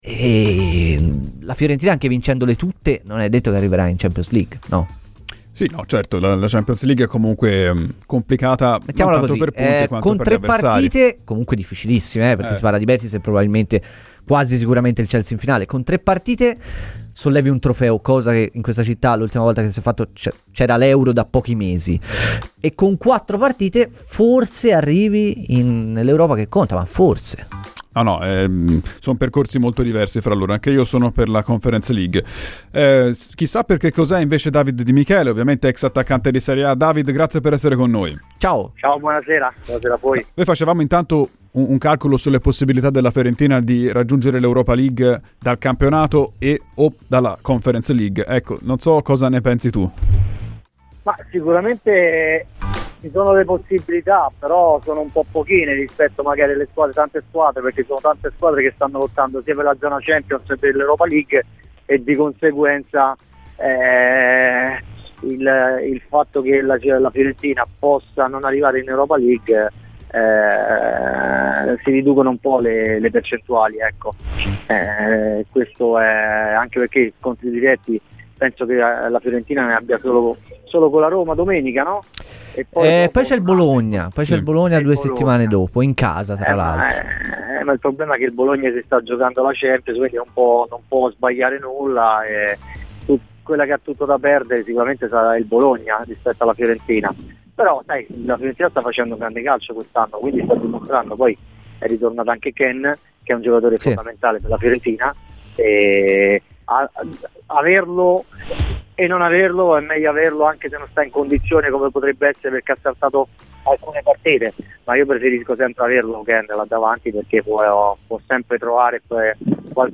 L'ex attaccante, tra le altre di Lecce e Udinese, David Di Michele è intervenuto ai microfoni di Radio FirenzeViola nel corso della trasmissione "I Tempi Supplementari" per analizzare l'attualità di casa viola.